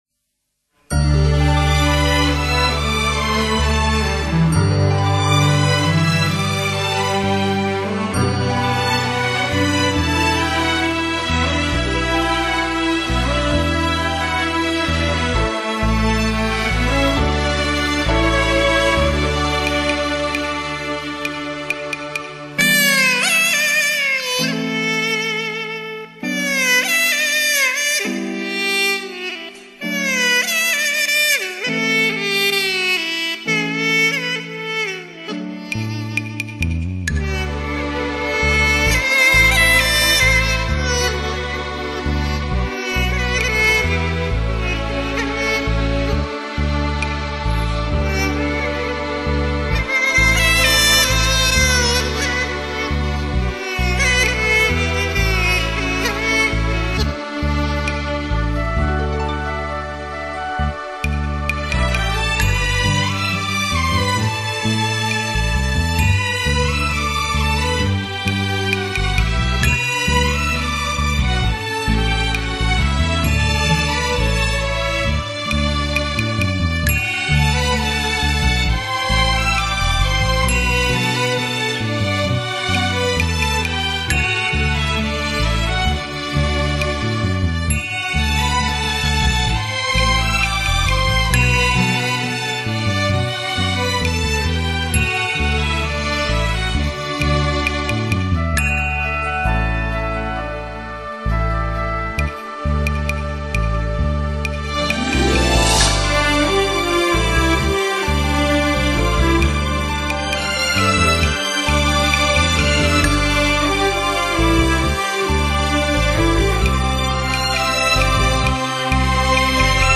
山西秧歌戏
那歌声...那旋律...悠扬飘荡...